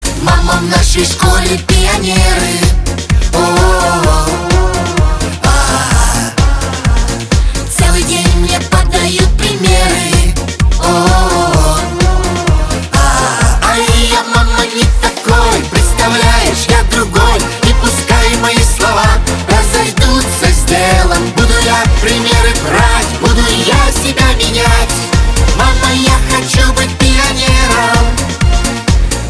• Качество: 256, Stereo
веселые